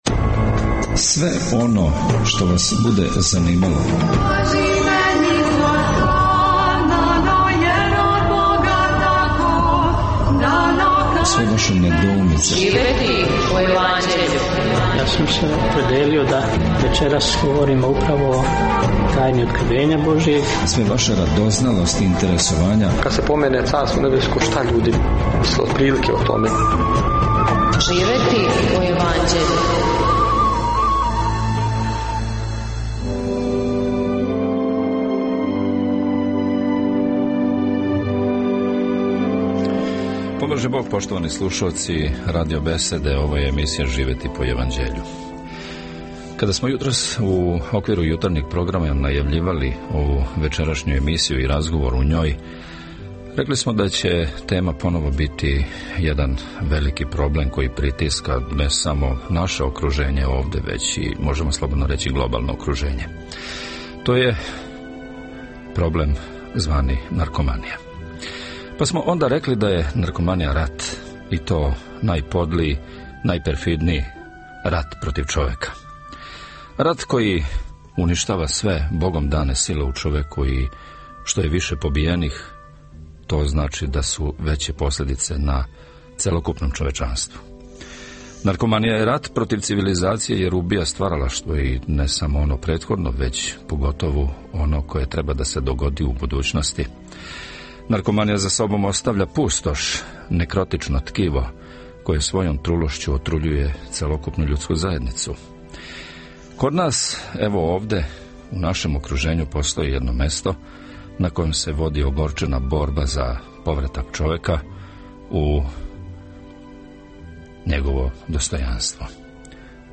Звучни запис емисије "Живети по Јеванђељу", емитоване 7. децембра 2010. године на Радио Беседи.